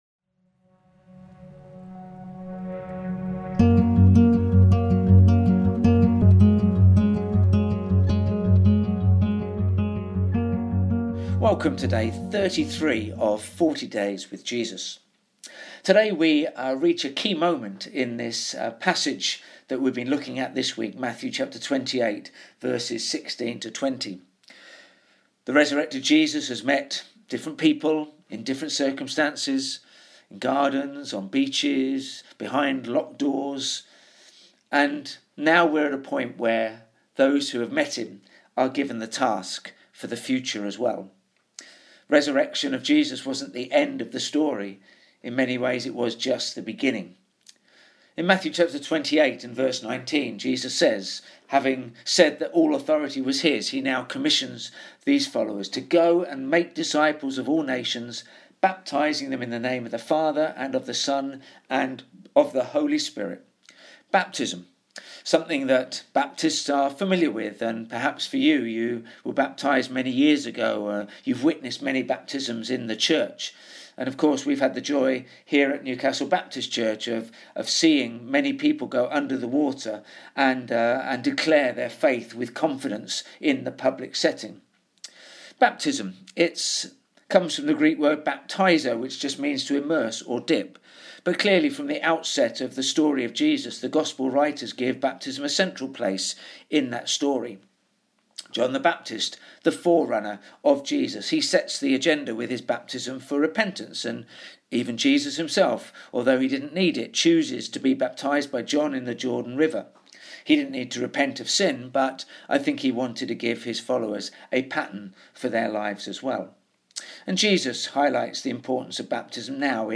We've started a preaching series on Sundays (from Easter Sunday) and these daily podcasts fill in some of the gaps from Sunday to Sunday. We will be posting short, daily reflections as we journey through the encounters people had with the risen Jesus.